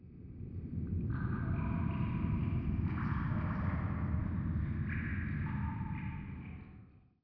cave5.ogg